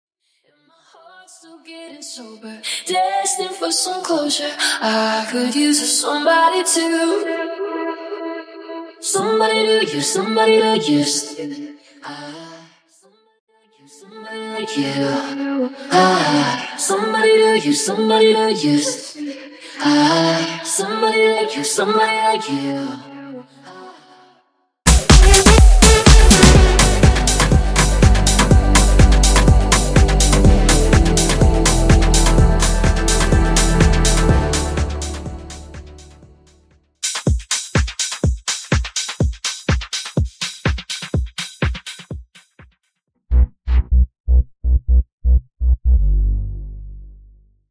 Acapella, Instrumental, Percussions & Bassline